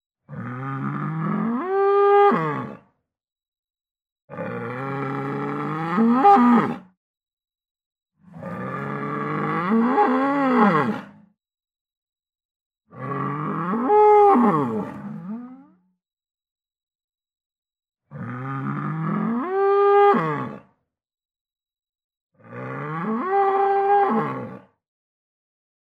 Звуки быка
Одинокий бык издает такой звук